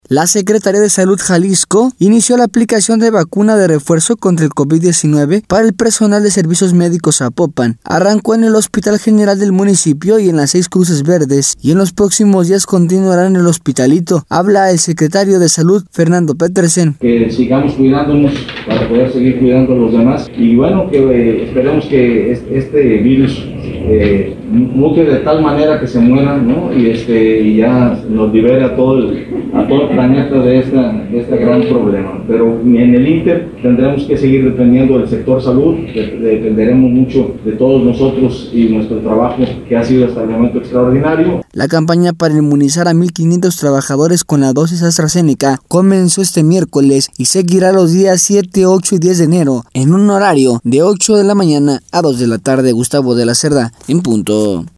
La Secretaría de Salud Jalisco, inició la aplicación de vacuna de refuerzo contra el Covid 19, al personal de Servicios Médicos Zapopan, arrancó en el hospital del municipio, y en las seis cruces verdes del municipio, y en los próximos días, continuará en el Hospitalito. Habla el secretario de Salud, Fernando Petersen: